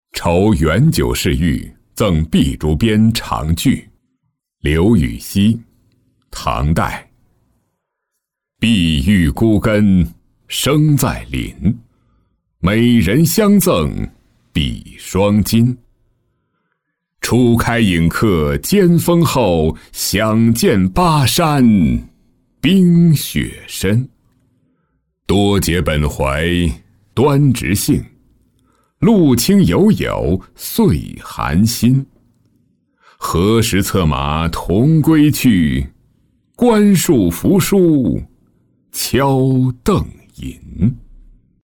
酬元九侍御赠璧竹鞭长句-音频朗读